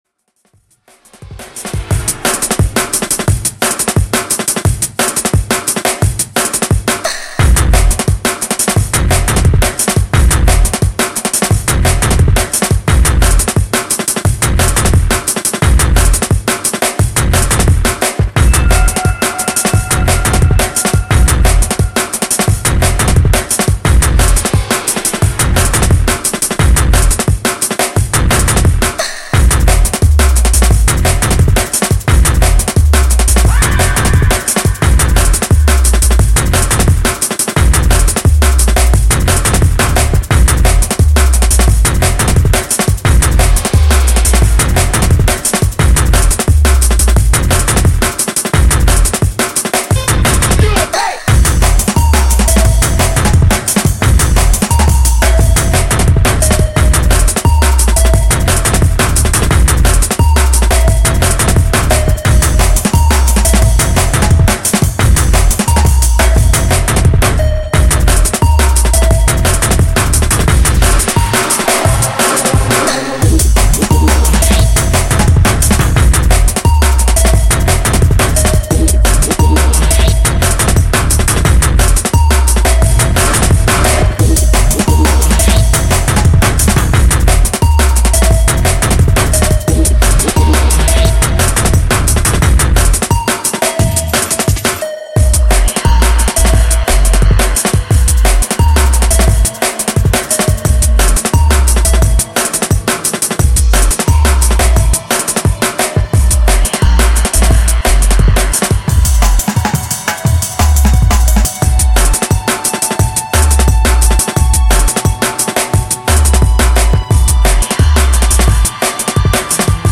Drum and Bass / Jungle